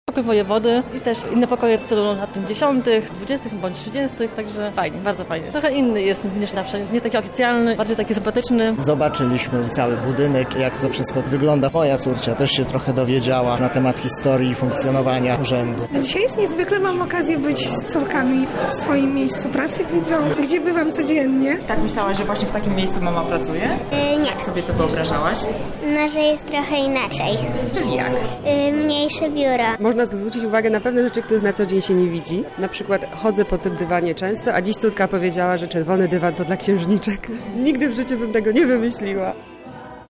Jakie wrażenia wywarł Urząd na mieszkańcach Lublina?